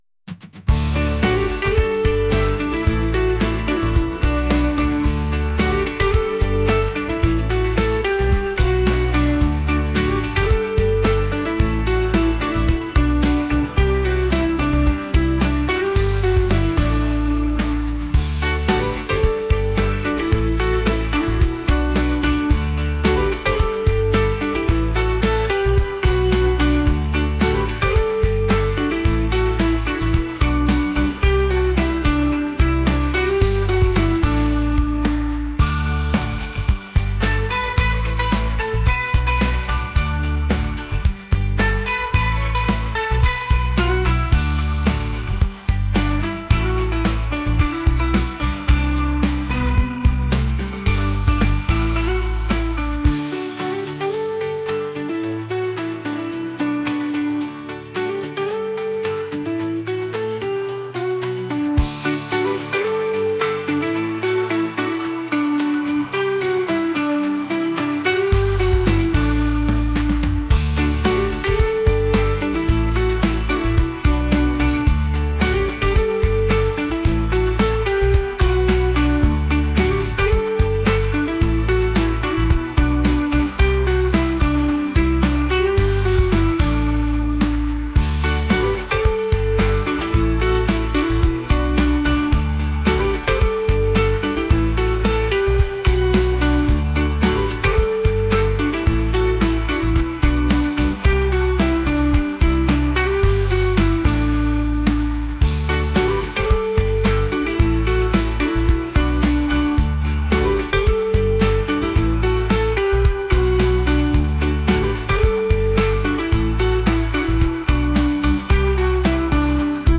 pop | acoustic | folk